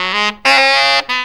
COOL SAX 8.wav